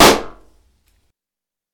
Balloon-Burst-01
balloon burst pop sound effect free sound royalty free Sound Effects